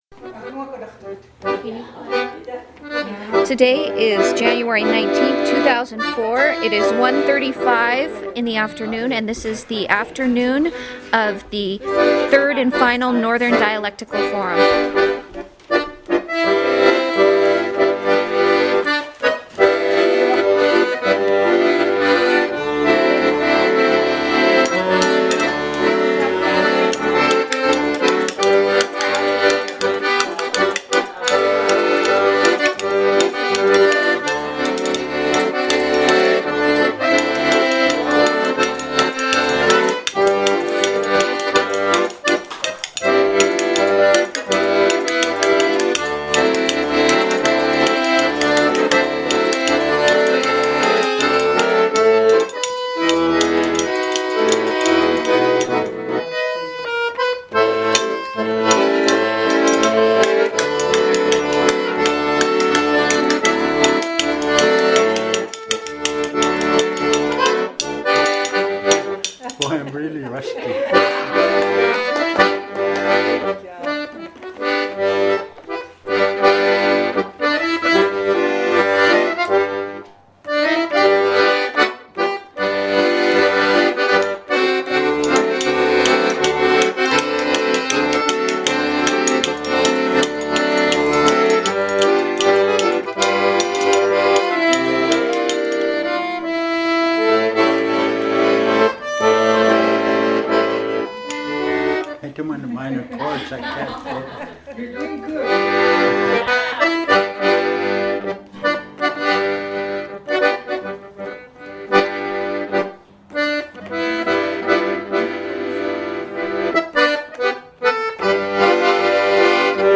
First Taping of Northern Dialect Forum 2A Part 1 | Alutiiq Museum Collections
Access audio Description: Original Format: MiniDisc (AM470:125A) Migration: CD (AM470:125B) Recording length (file size, time): 840.2 MB; 1:19:23 Location: Location Description: Kodiak, Alaska